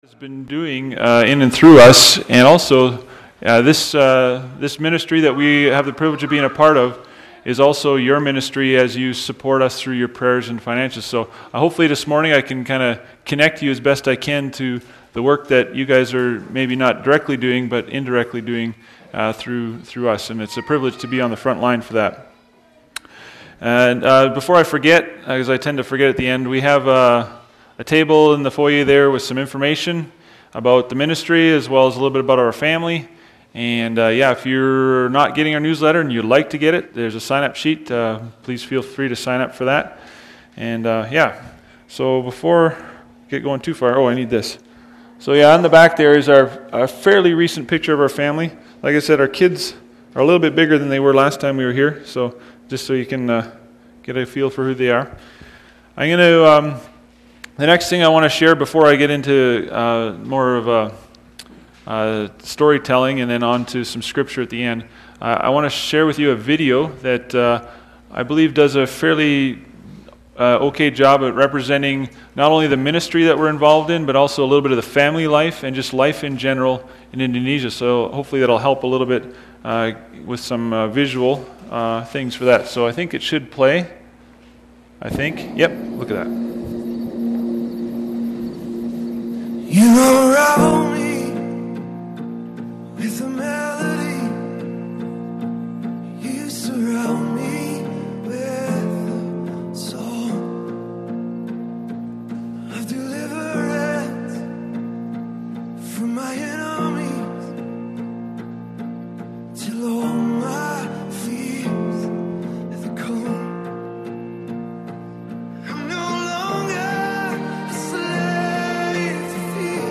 Posted in Sermons .